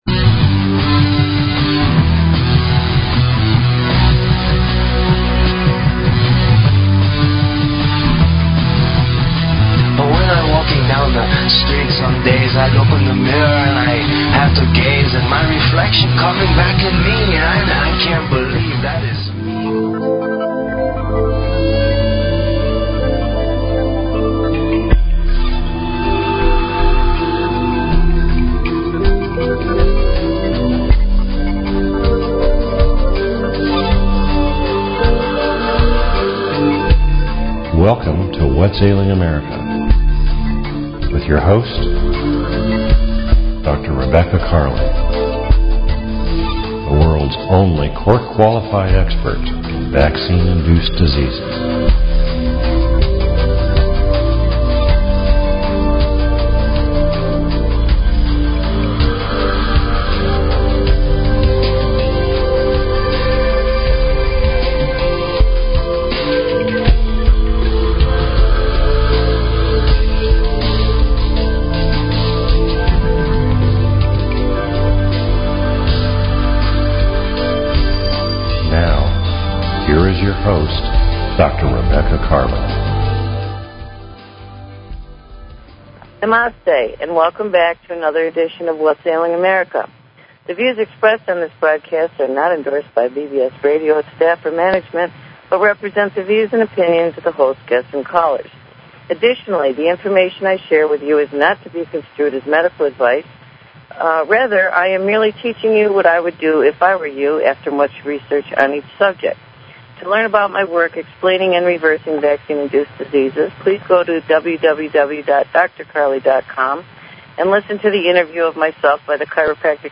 Talk Show Episode, Audio Podcast, Whats_Ailing_America and Courtesy of BBS Radio on , show guests , about , categorized as